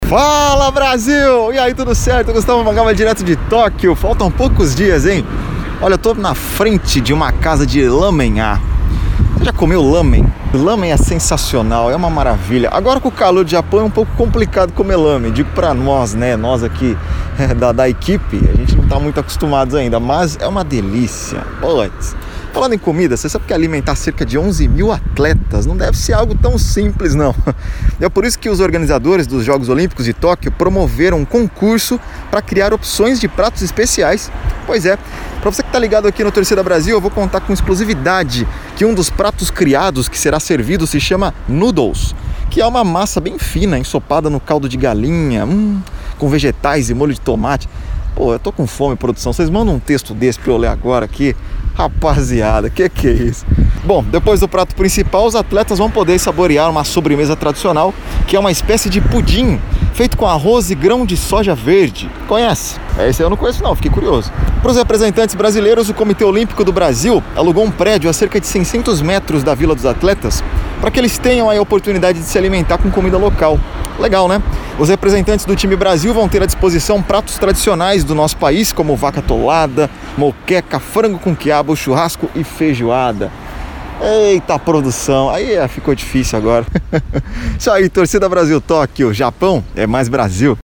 TORCIDA BRASIL – Boletim direto de Tóquio